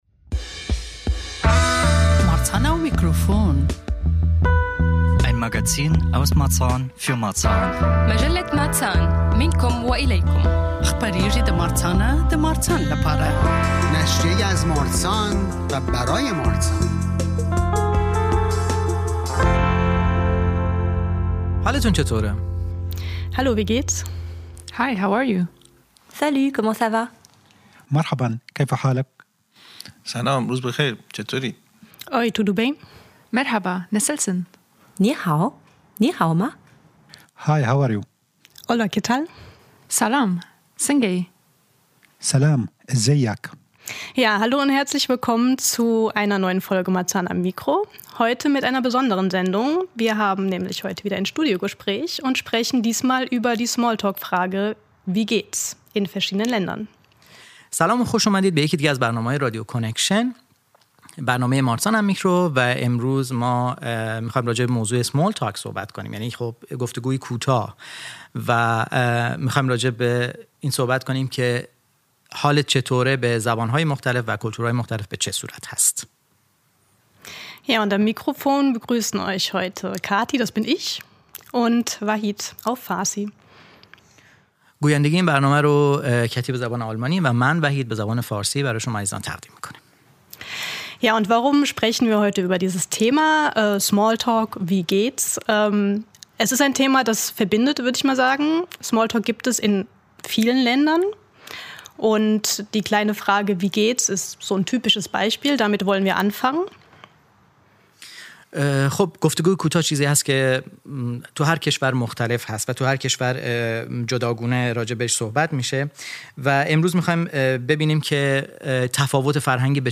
Darüber und noch über viel mehr sprechen wir in der heutigen Folge Marzahn am Mikro in einem Studiogespräch. Dabei möchten wir auch kurz darauf eingehen, warum Smalltalk überhaupt für uns Menschen wichtig ist - und welche Rolle er beim Lernen einer neuen Sprache spielt.